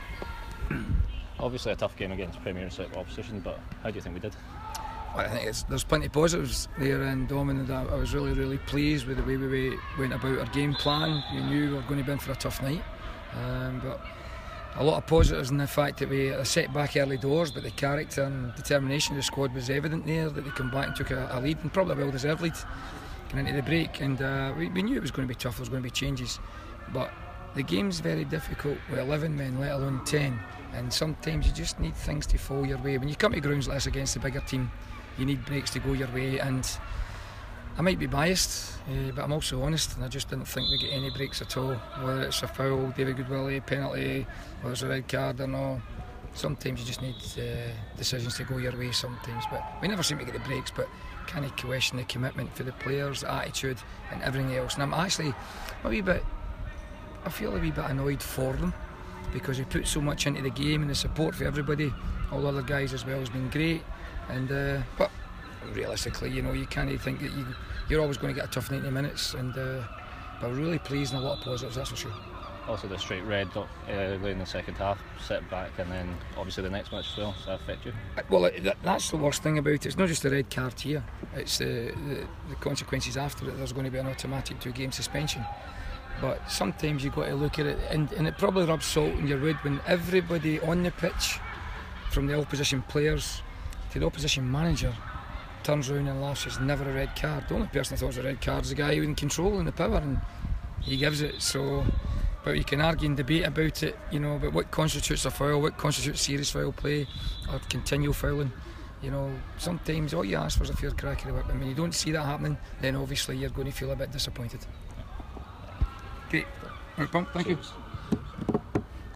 press conference after the Betfred Cup match.